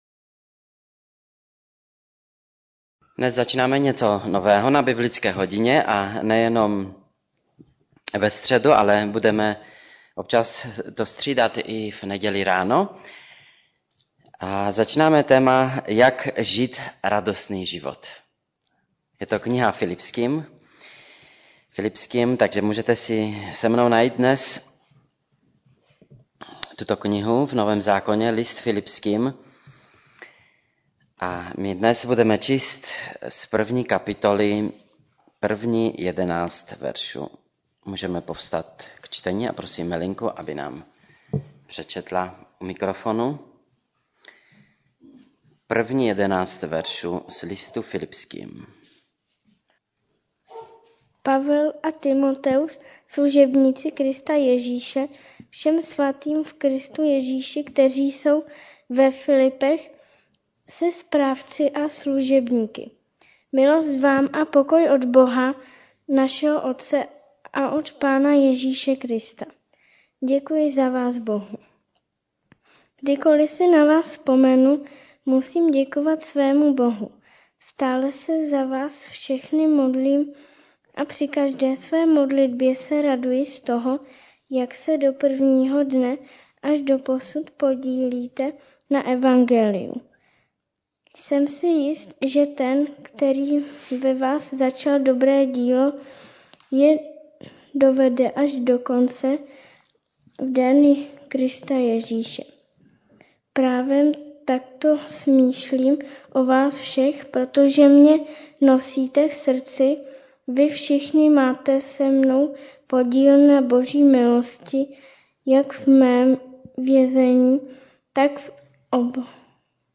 Radost ze vztahu Kategorie: Kázání MP3 Zobrazení: 3393 Jak žít radostný život- Filipským 1:1-11 Radost ze vztahu Předchozí článek: 2.